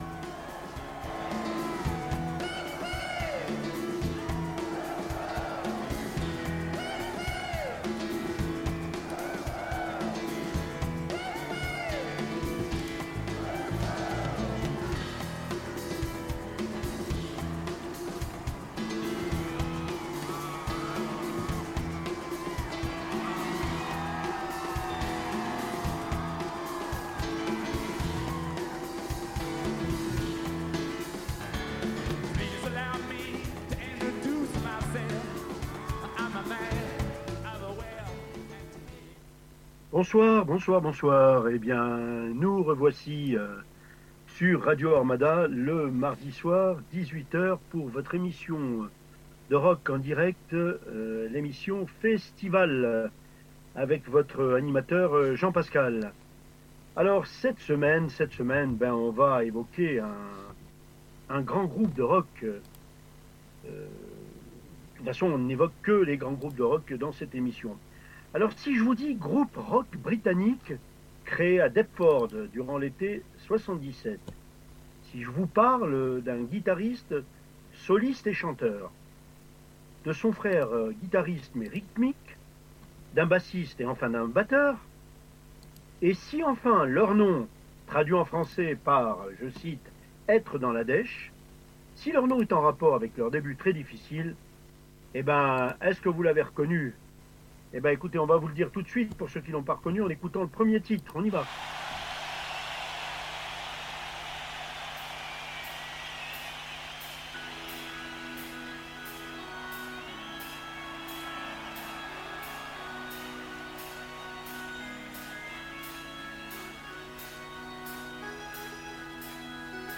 1h15 minute de son rock